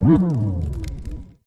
Grito